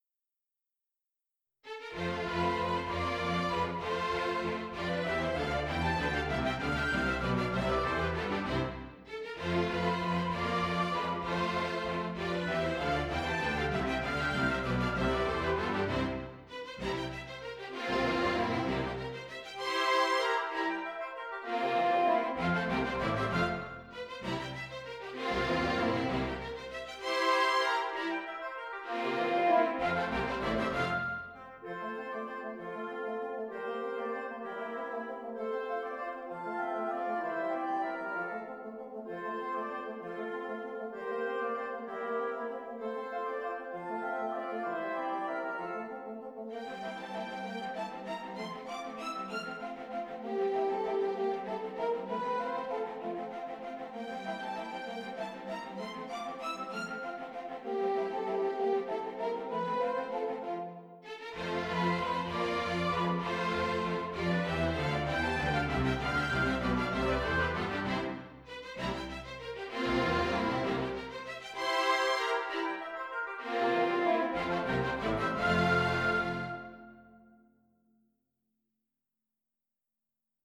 I did now the same with the first 5 dances on the basis of these old midi files by simplifying them a bit and using my tool NotePerformer.
My renditions may have too much reverb, should I reduce the hall effect?
WoO13-5_orch.mp3